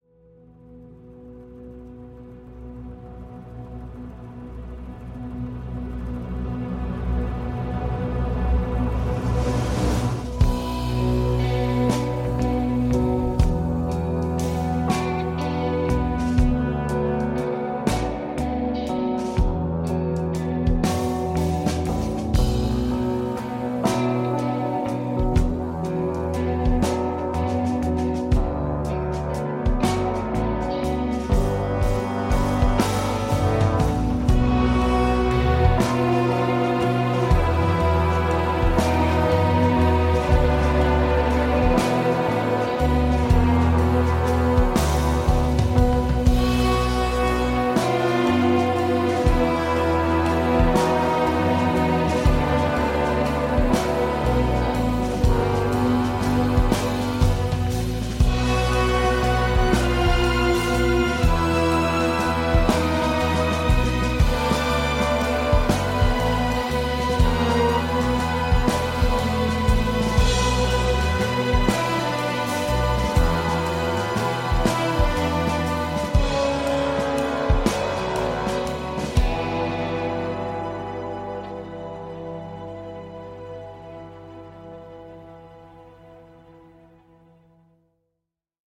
C’est sombre, voir lugubre.